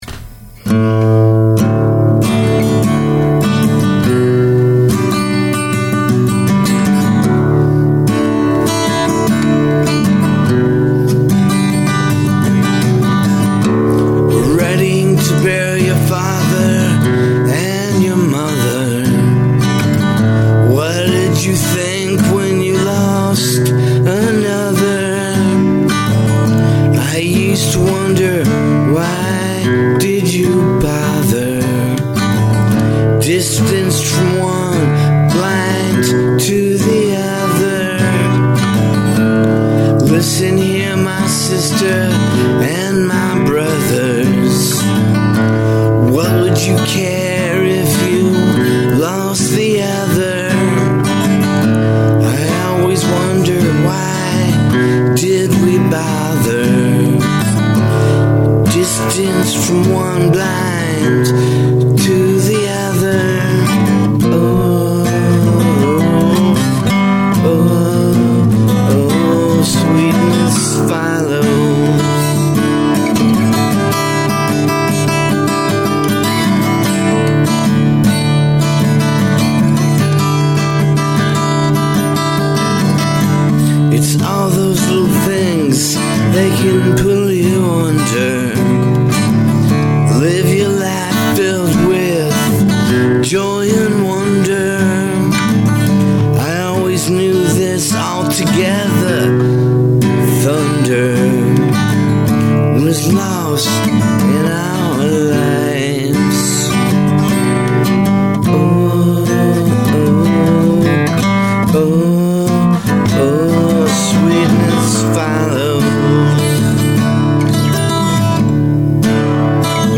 also my voice is not up to par right now.
satiate yourself with this sad song.